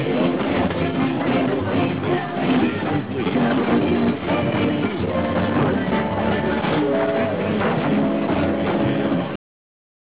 This page contains DX Clips from the 2008 DX season!